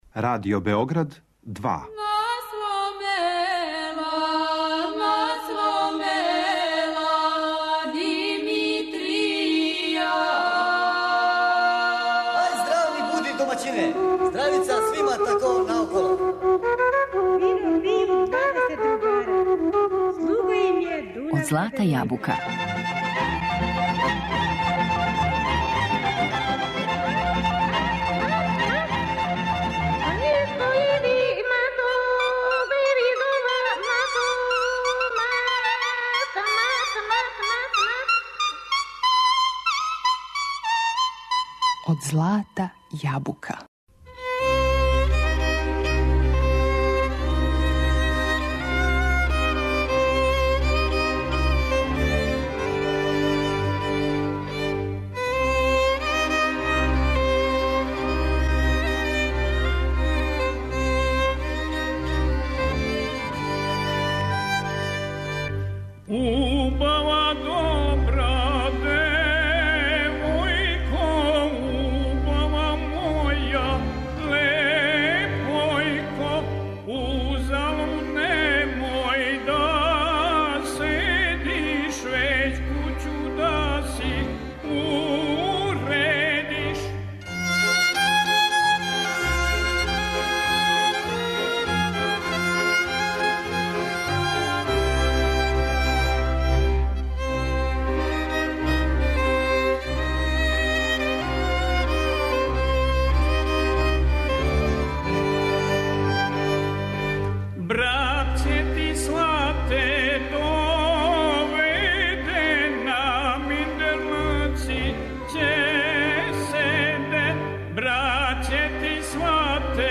Емисија изворне народне музике